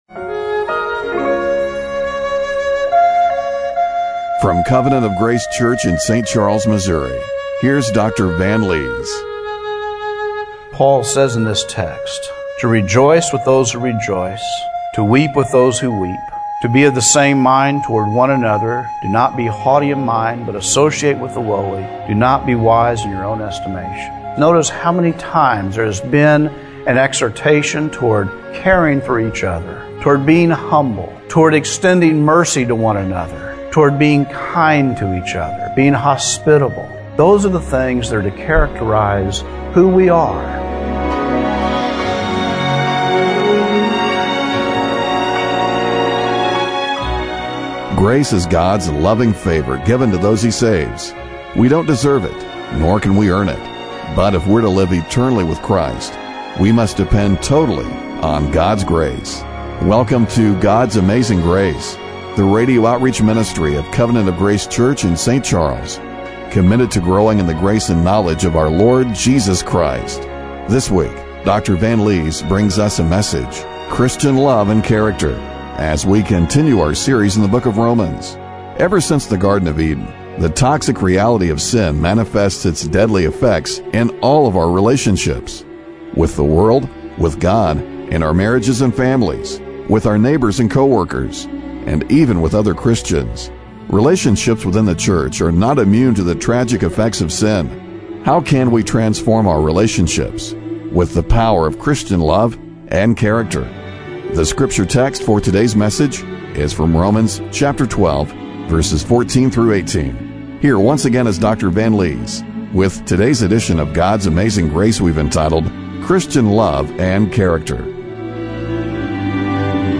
Romans 12:14-18 Service Type: Radio Broadcast How can we transform our relationships with the power of Christian love and character?